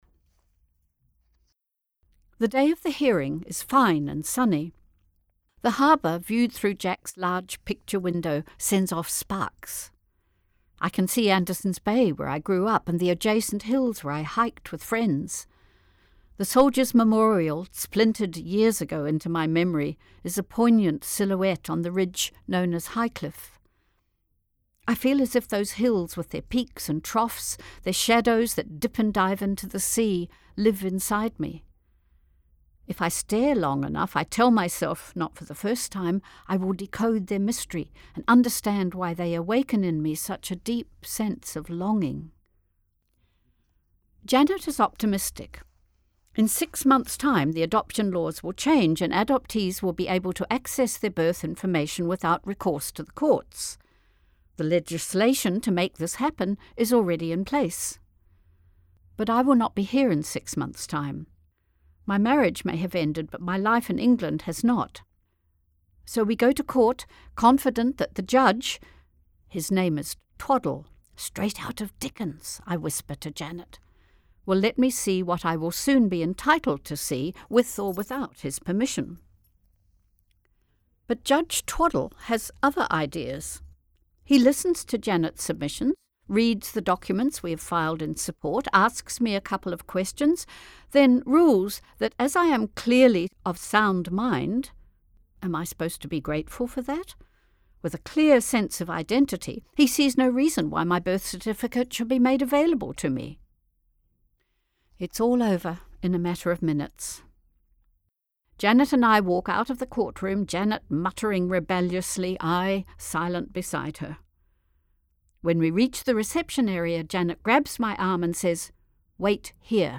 Available as an Audiobook here